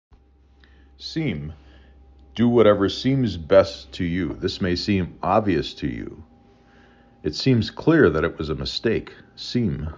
s E m